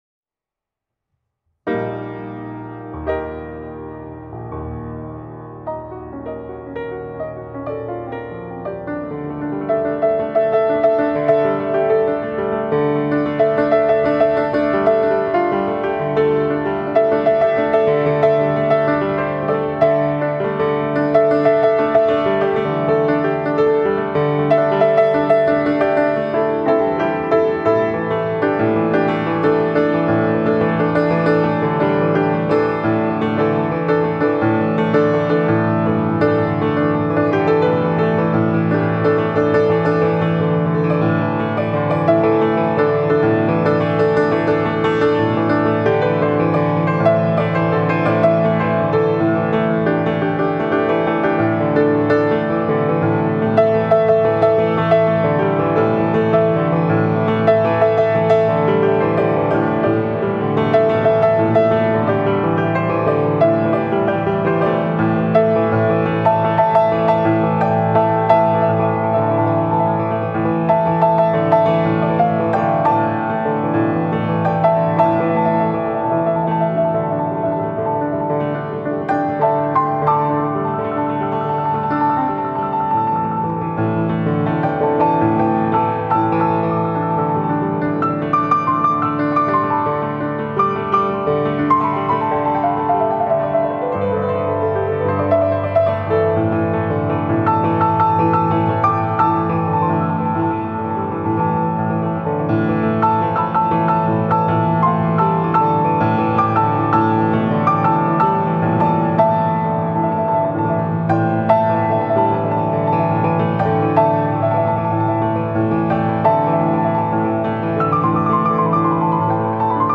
عزف على البيانو